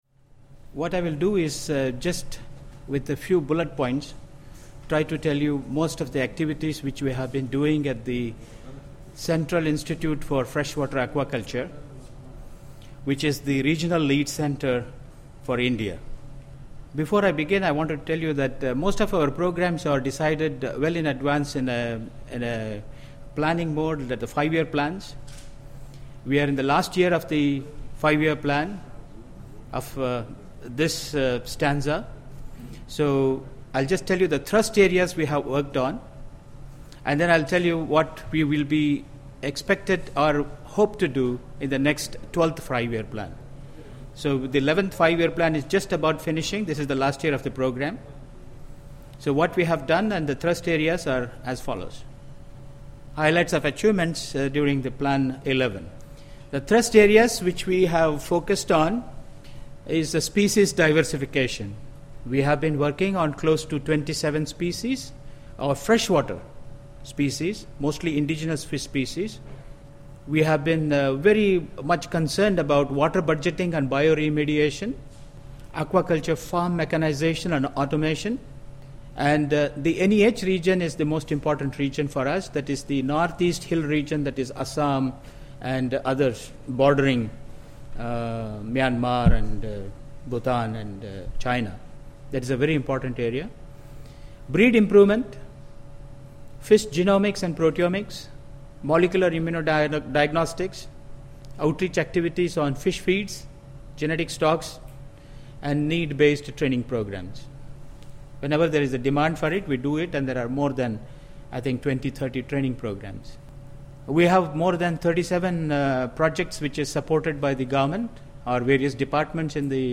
The presentations were made at the 22nd NACA Governing Council Meeting in Cochi, Kerala, from 9-11 May 2011, India.